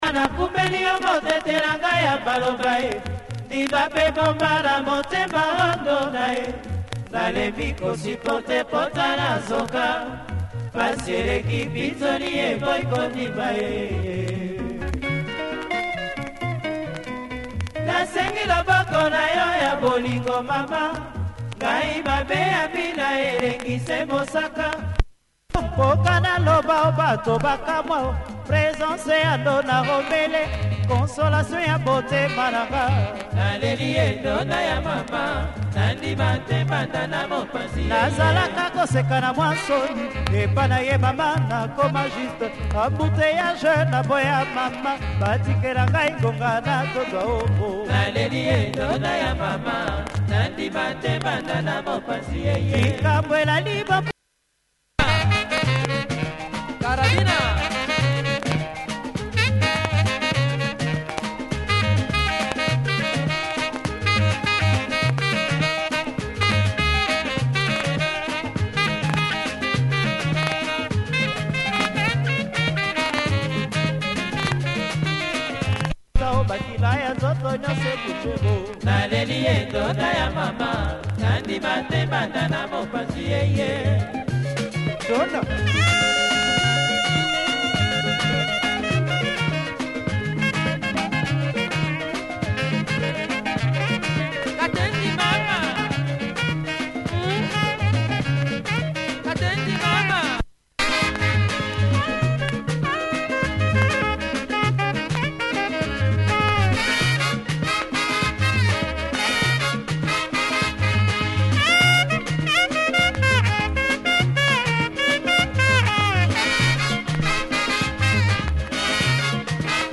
has a few marks that affect play